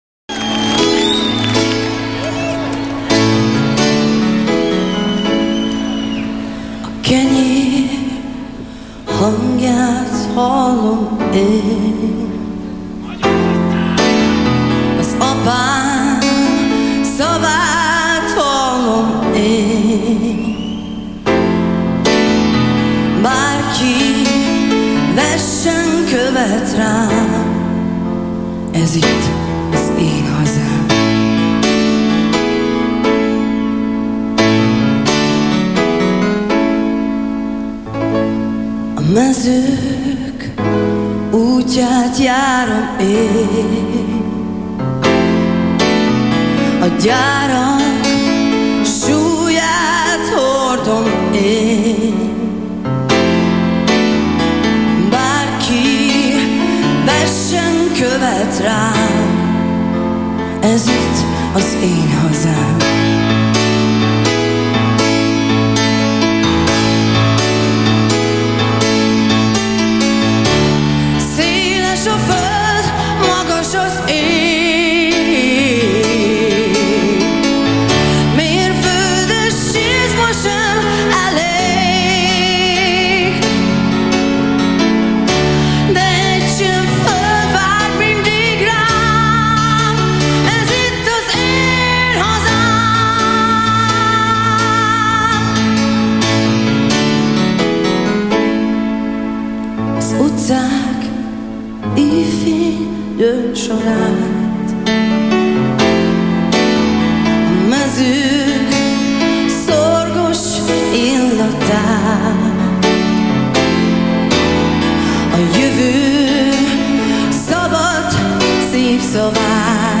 Hangok - koncert- és TV felvételek
TV2, Millenáris
stereo